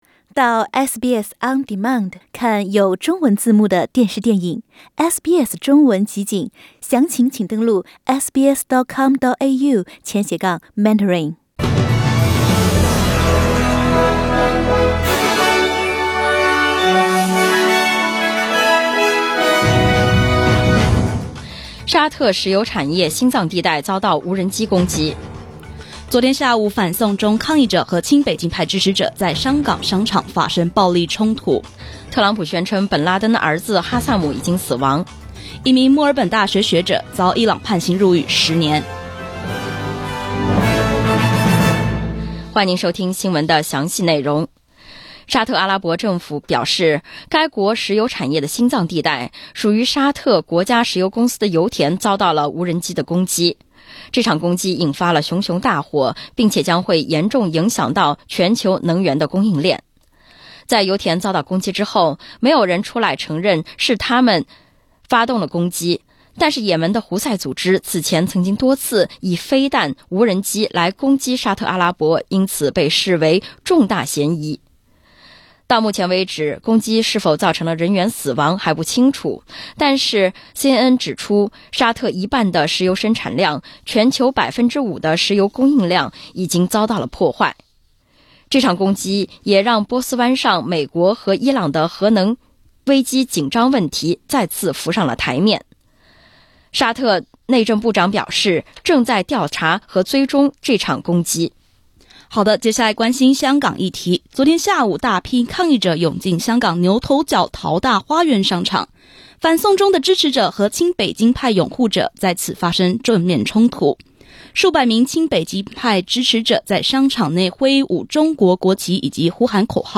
SBS早新闻（9月15日）